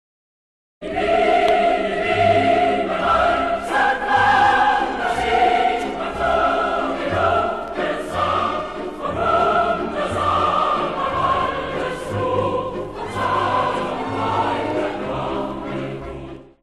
Konzertwalzer
für gemischten Chor und Orchester
Besetzung: gemischter Chor, Klavier (ggf. 4-händig)